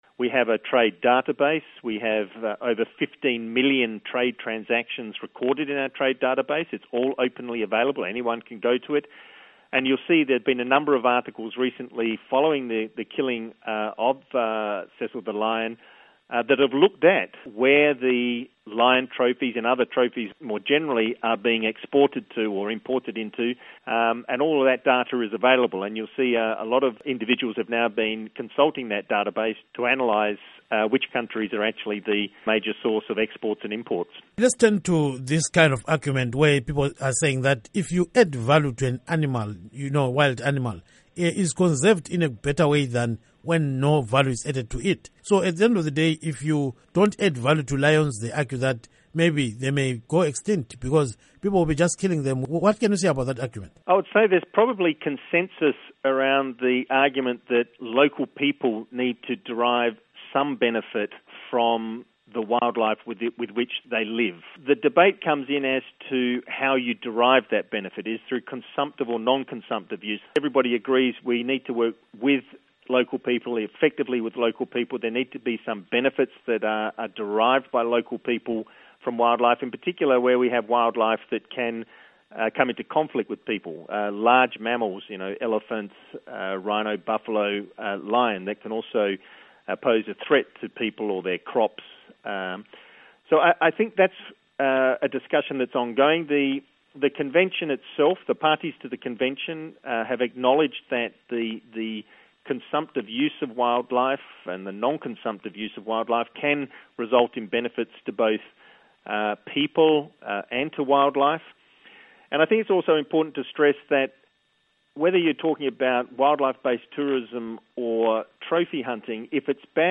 Interview With John Scanlon on Animal Conservation